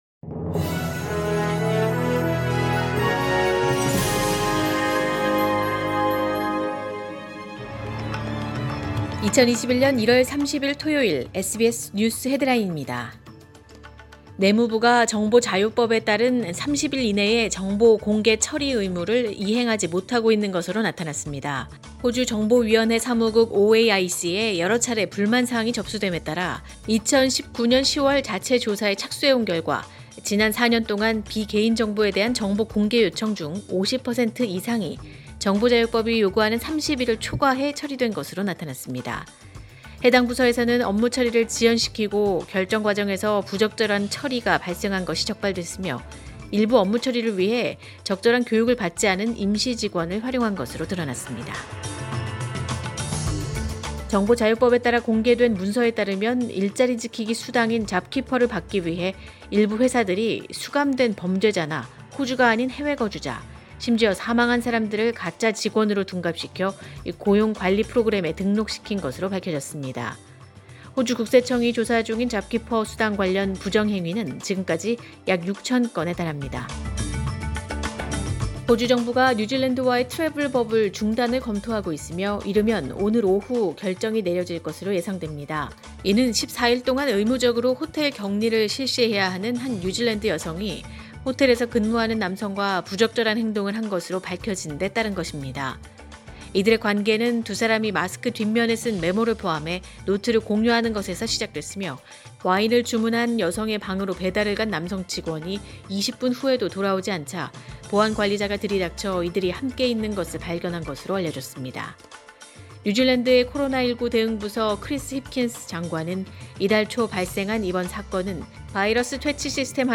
2021년 1월 30일 토요일 SBS 뉴스 헤드라인입니다.